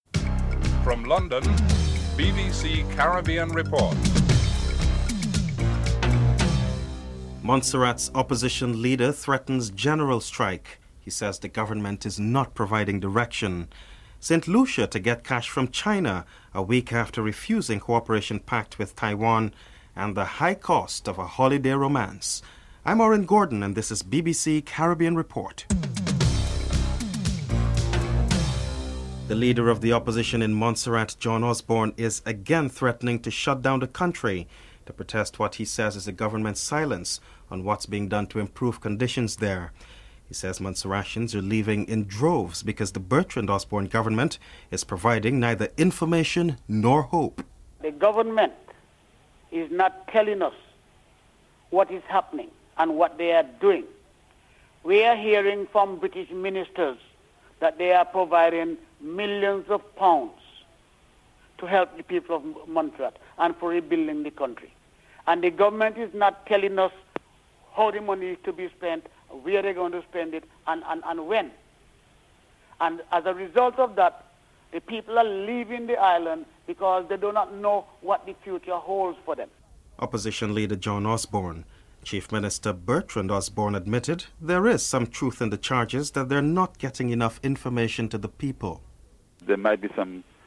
The Beatles' producer, George Martin is interivewed.